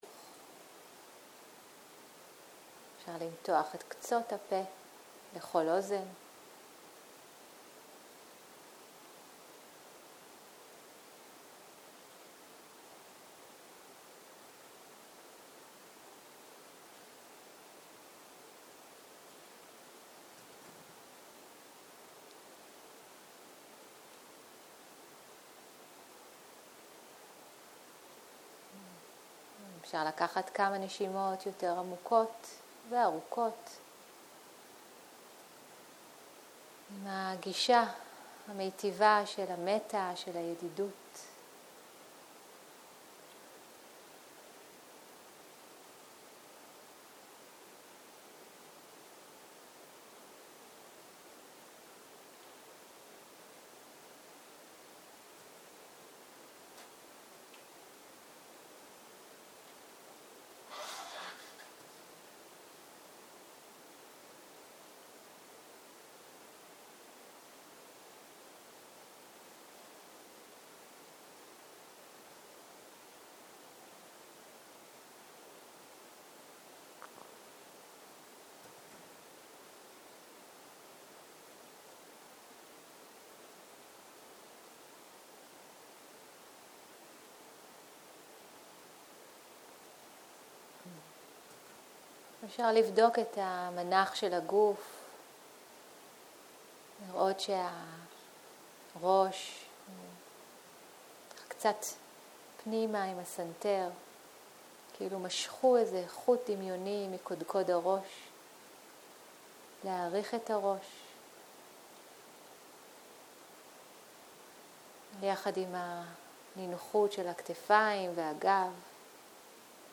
צהרים - מדיטציה מונחית